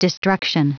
Prononciation du mot destruction en anglais (fichier audio)
Prononciation du mot : destruction